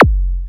normal-hitwhistle.wav